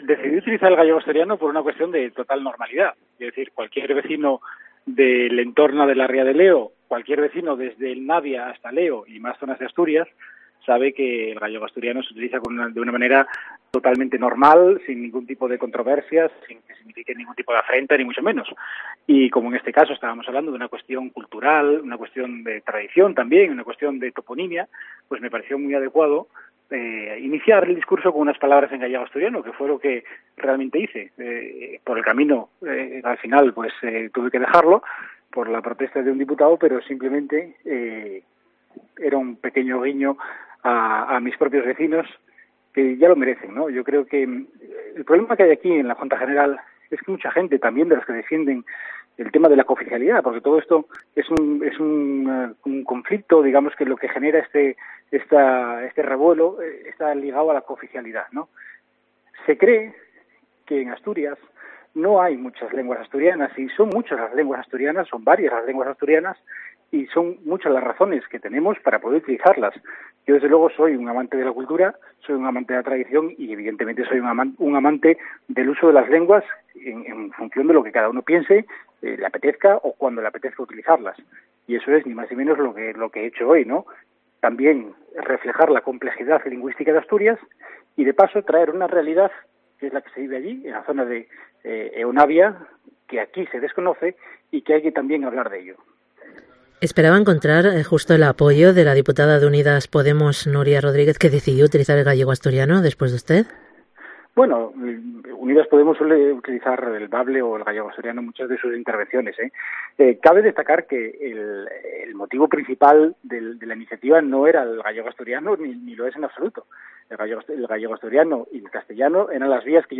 ENTREVISTA con el diputado por el Occidente, Álvaro Queipo